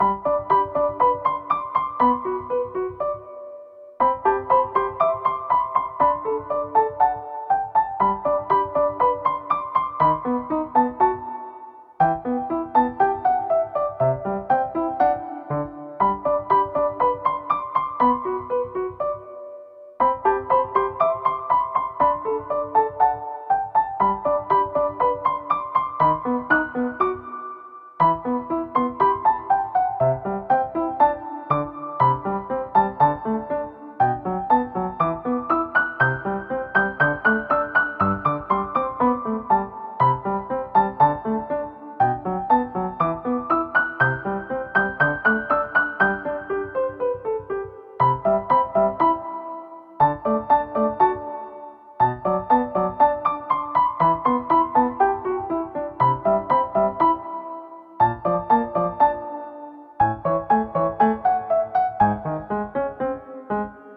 - 生ピアノ（生演奏） シリアス